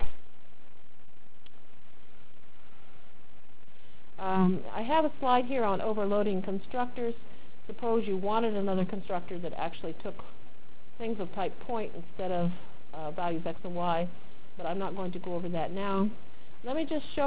Delivered Lecture